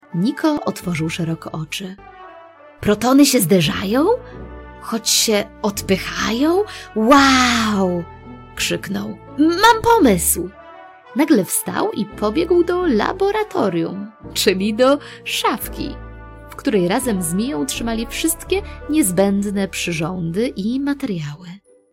Wykonuję również dubbing do gier i animacji.